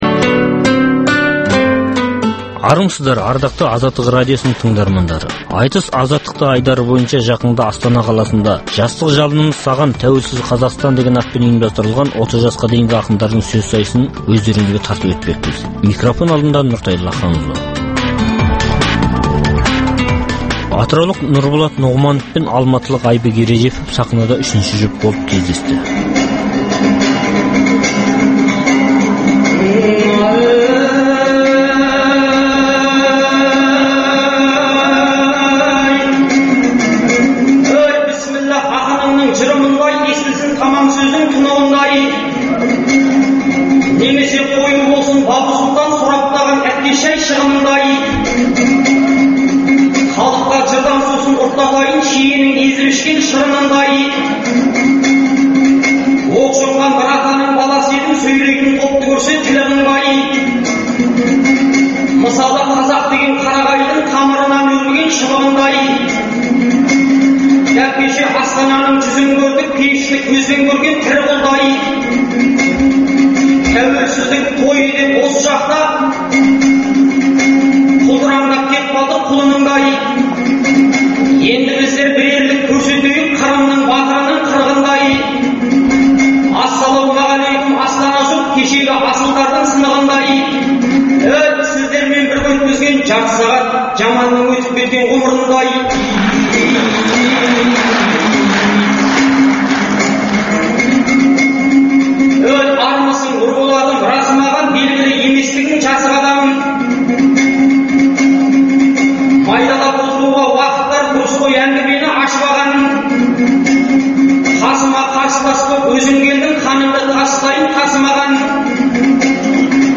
Айтыс Азаттықта